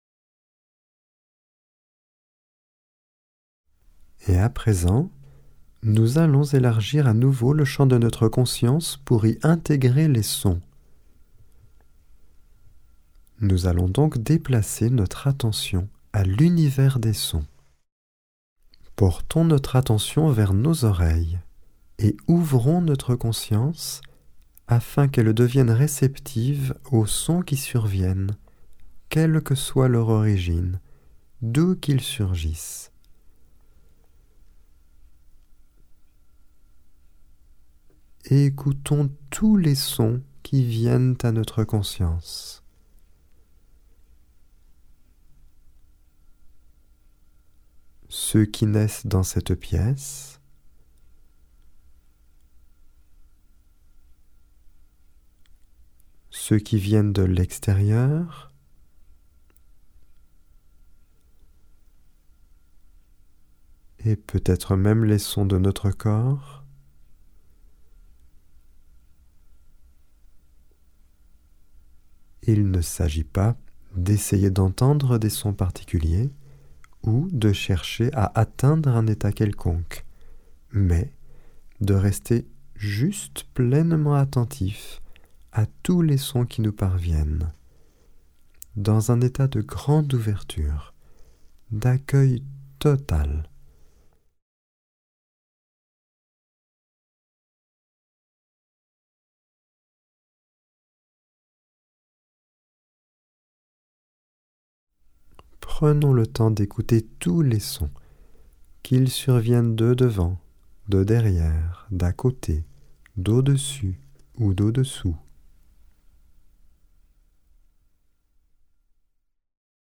Genre : Meditative.
Etape 6 – Méditation assise complète 3